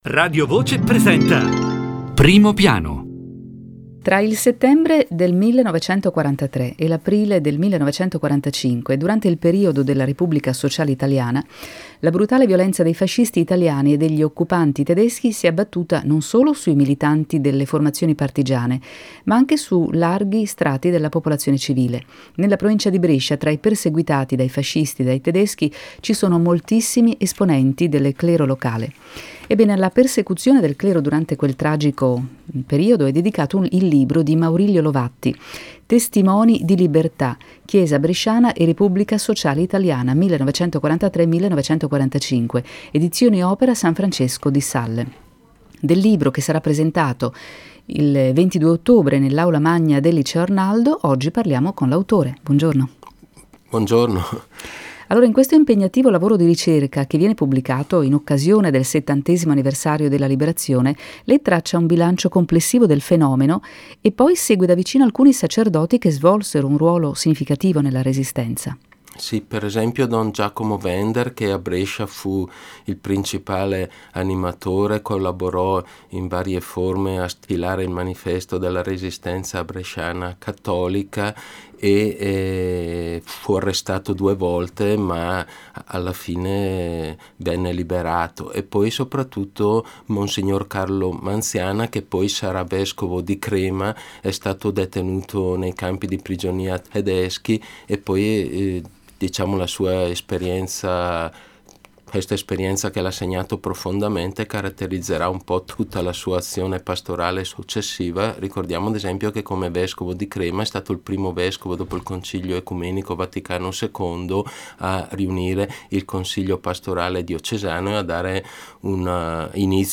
Intervista a Radio Voce Brescia